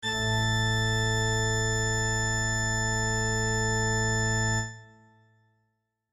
LA-110-a-5-octavas.mp3